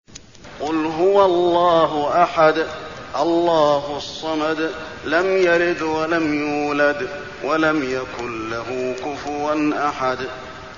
المكان: المسجد النبوي الإخلاص